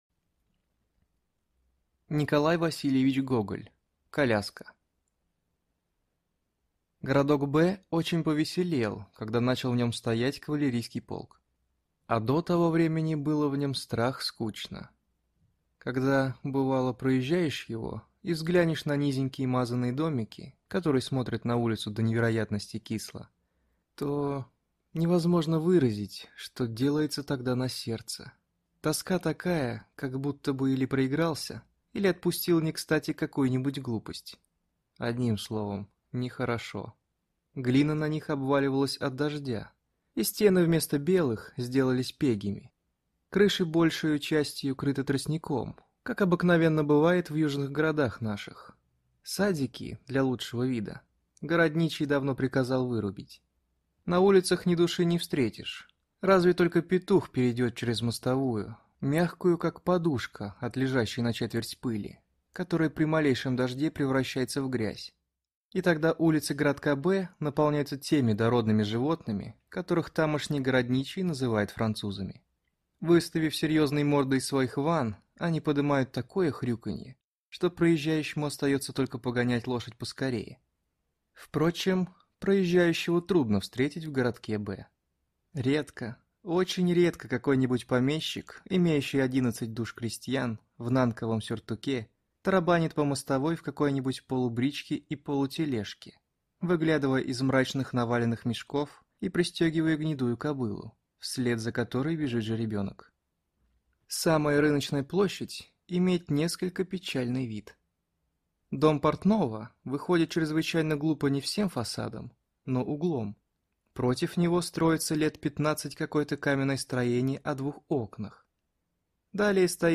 Аудиокнига Коляска | Библиотека аудиокниг